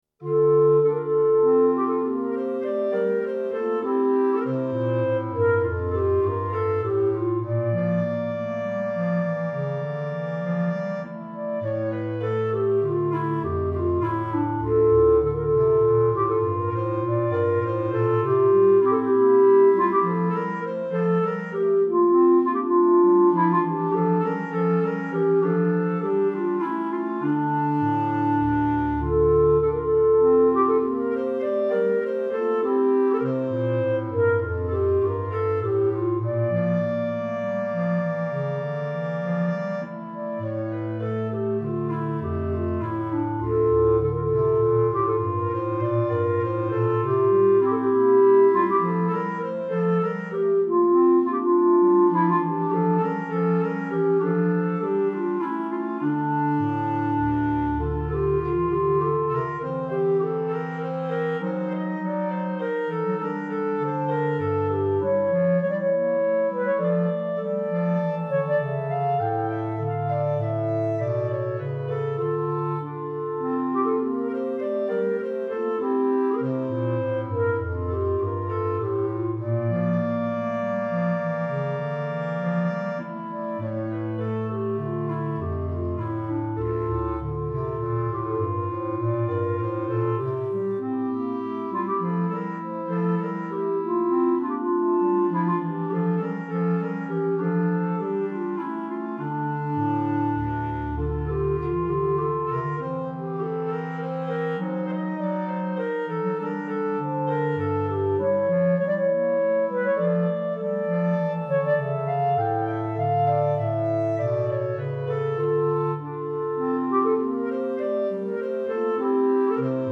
Right click to download Minuet minus Bass Clarinet